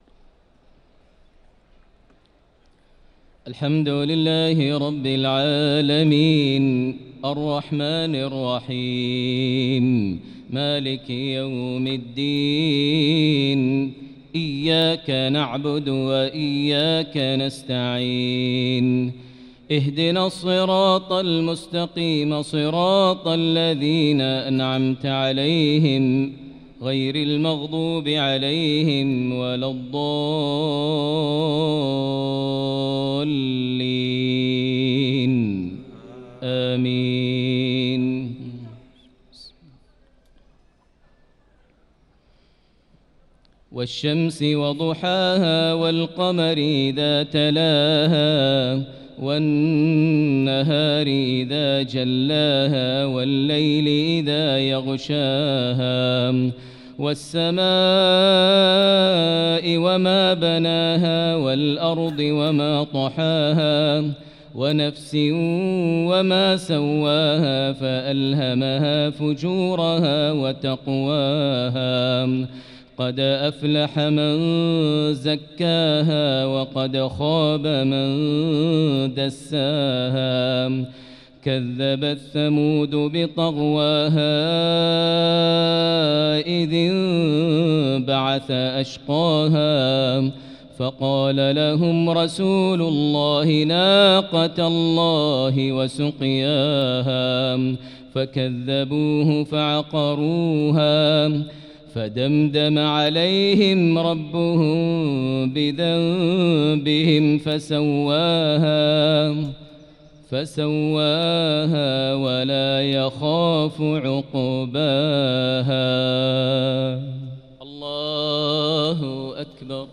صلاة العشاء للقارئ ماهر المعيقلي 24 رمضان 1445 هـ
تِلَاوَات الْحَرَمَيْن .